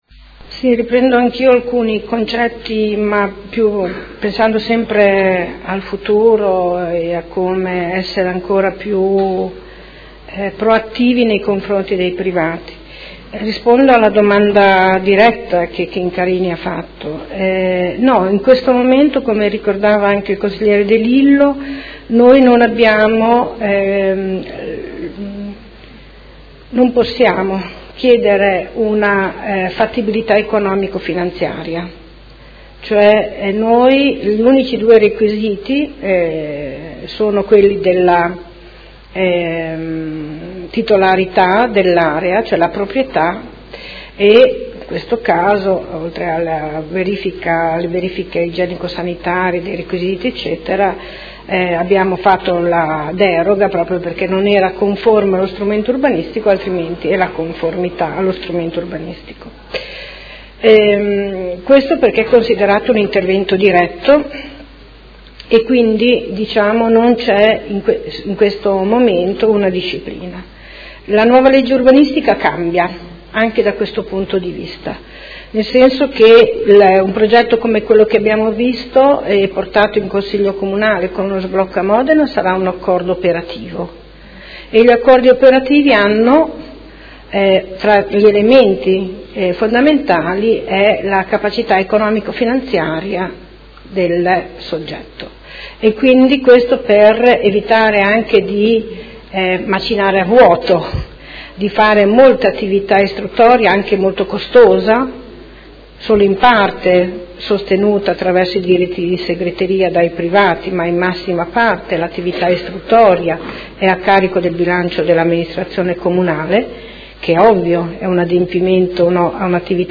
Seduta del 3/05/2018. Conclude dibattito su interrogazione del Gruppo M5S avente per oggetto: Situazione di degrado alla Madonnina